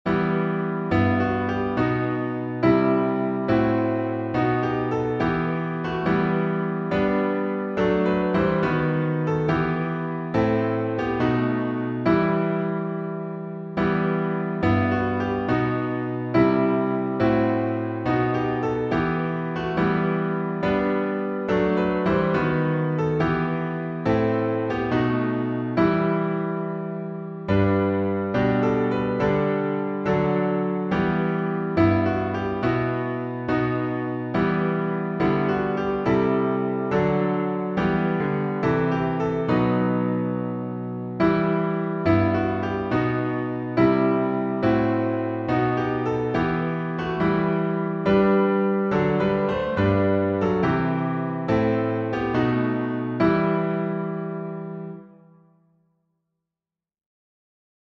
#5114: O the Deep, Deep Love of Jesus — alternate timing — Ebenezer | Mobile Hymns
Words by Samuel Trevor FrancisTune: EBENEZER by Thomas J. Williams (1869-1944)Key signature: G major (1 sharp)Time signature: 4/4Meter: 8.7.8.7.D.Public Domain1.